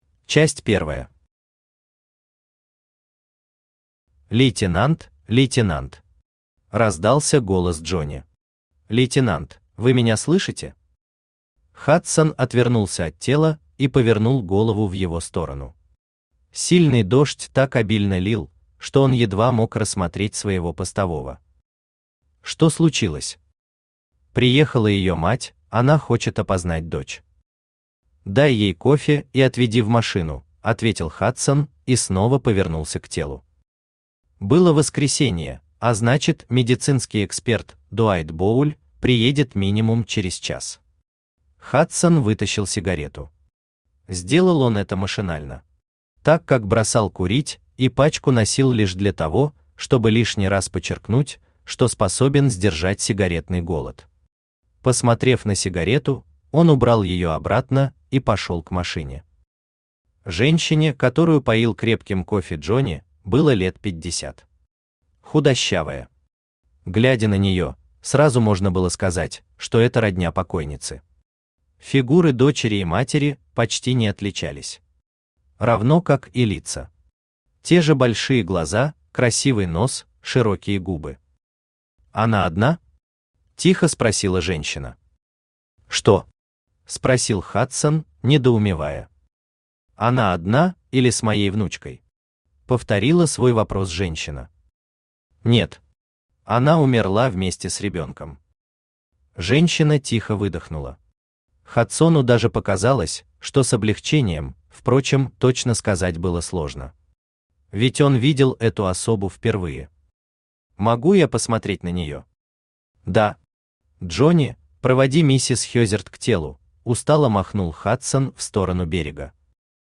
Аудиокнига Багровый дождь | Библиотека аудиокниг
Aудиокнига Багровый дождь Автор Даниил Заврин Читает аудиокнигу Авточтец ЛитРес. Прослушать и бесплатно скачать фрагмент аудиокниги